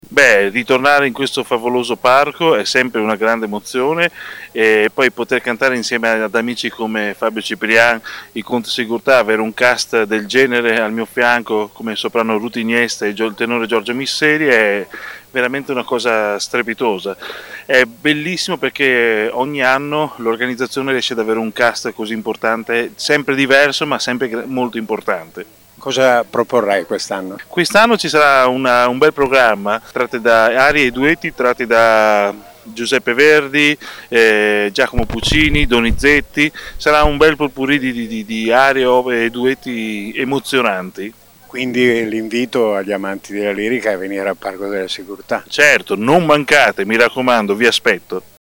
Le interviste del nostro corrispondente